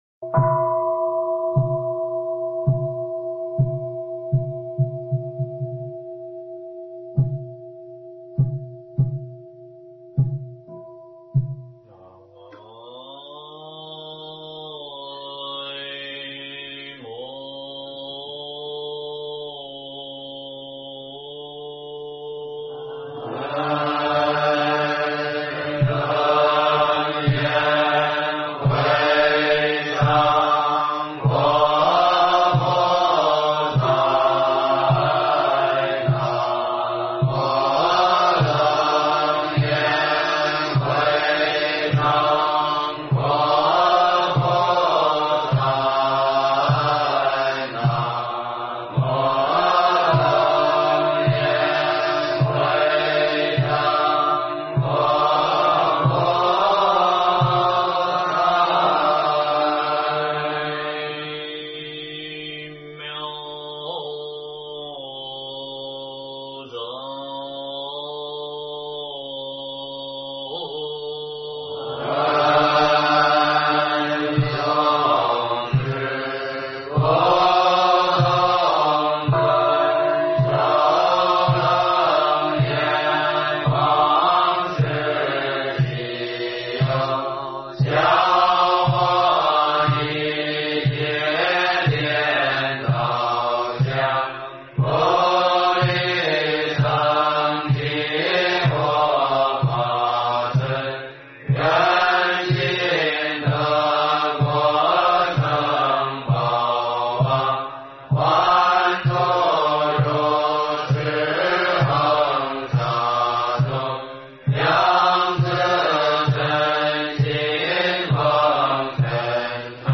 楞严咒(念诵）--海城大悲寺僧众